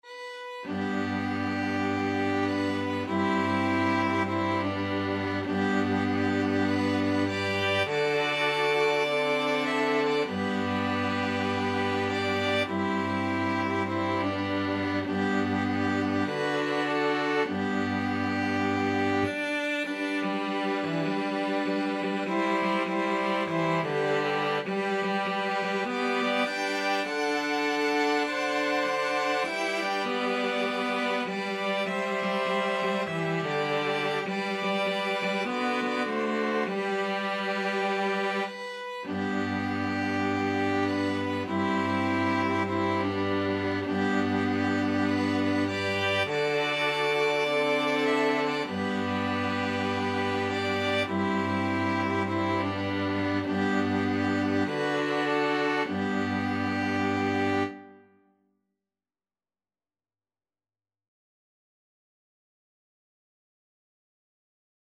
Violin 1Violin 2ViolaCello
4/4 (View more 4/4 Music)
Swung
String Quartet  (View more Easy String Quartet Music)